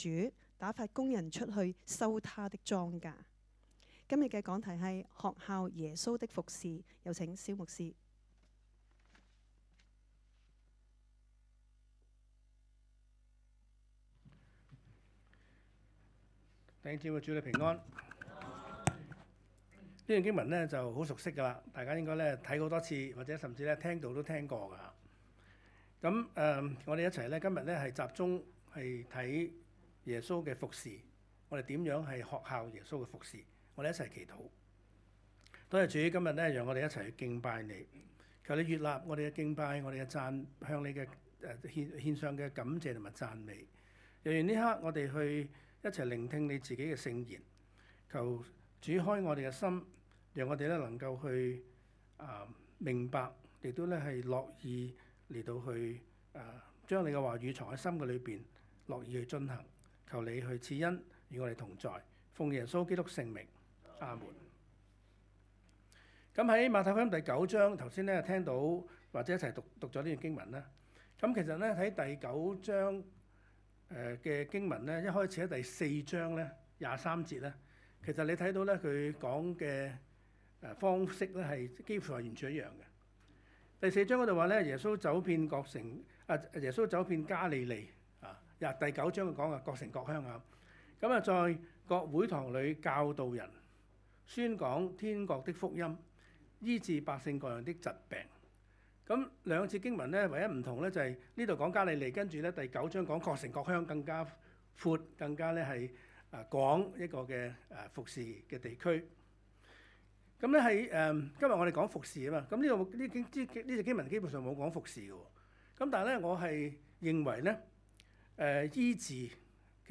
講道 : 學效耶穌的服事